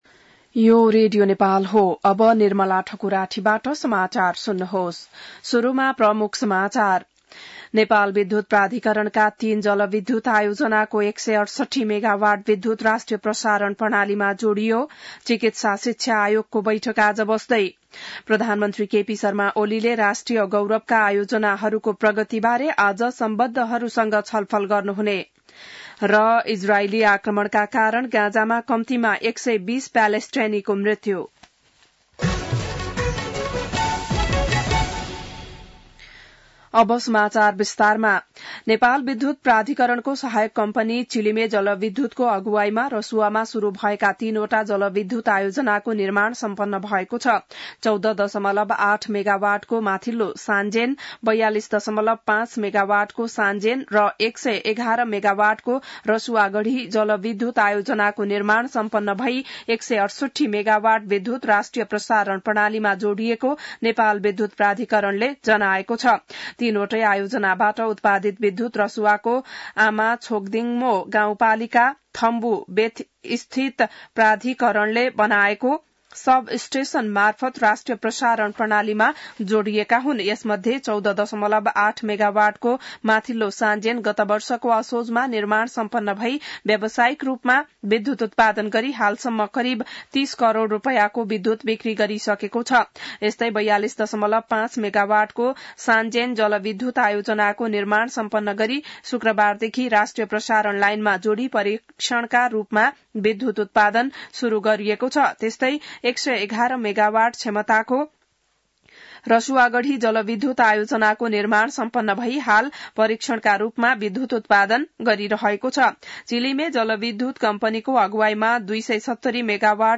बिहान ९ बजेको नेपाली समाचार : १० मंसिर , २०८१